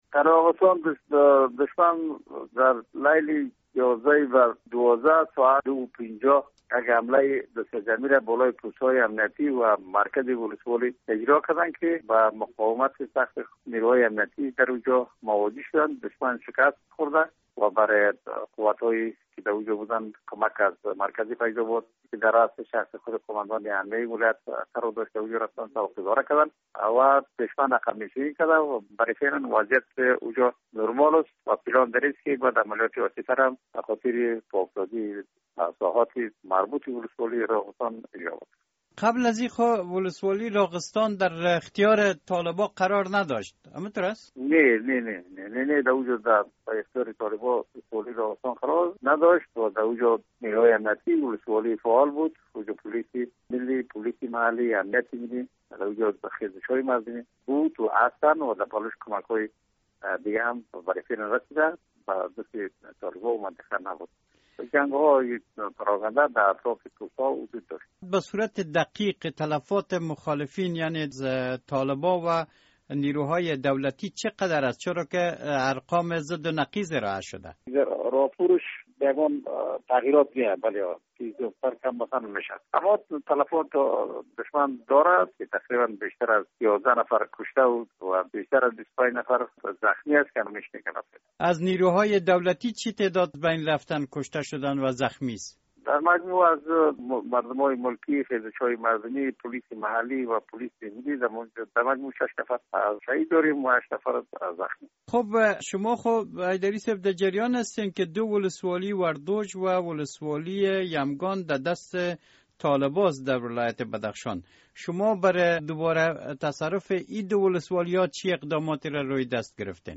مصاحبه با سخیداد حیدری آمر امنیت بدخشان: